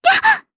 Kirze-Vox_Damage_jp_01.wav